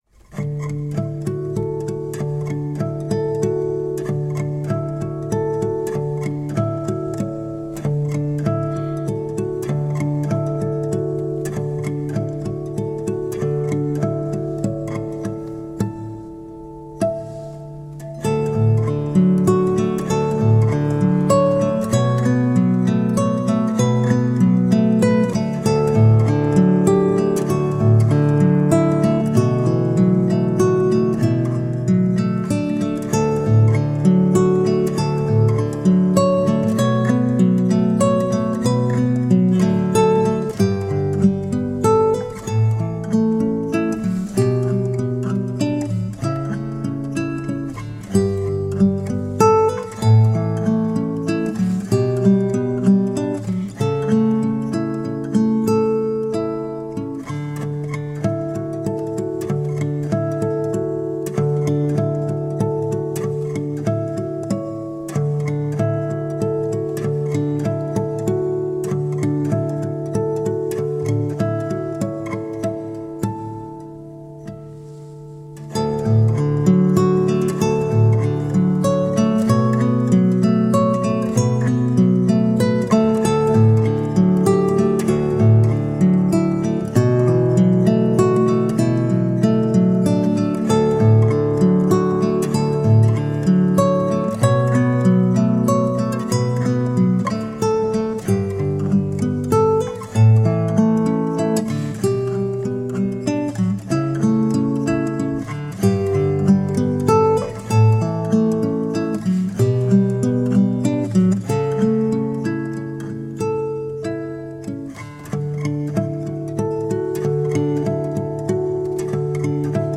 Acoustic new age and jazz guitar..
Hushed, contemporary, and so lovely
Played on nylon string guitar with open tuning
in a subdued, deeply reflective way